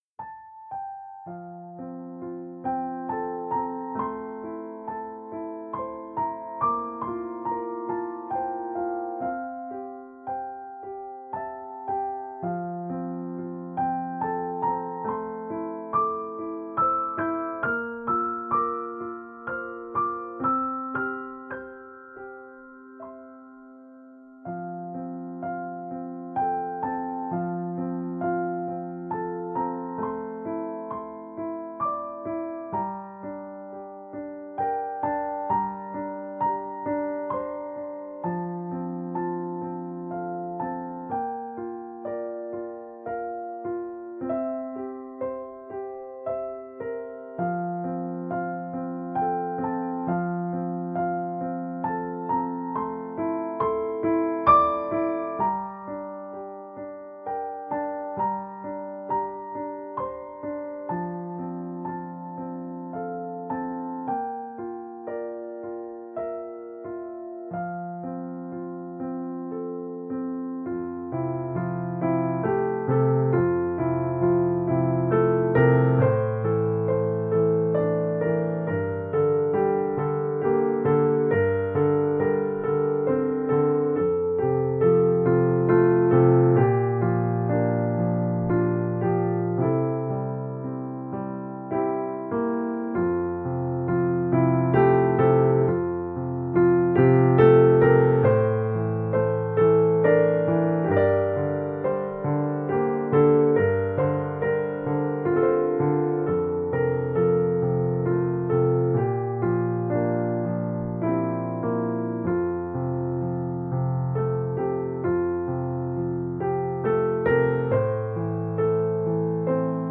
Voicing/Instrumentation: Piano Solo We also have other 77 arrangements of " Away In a Manger ".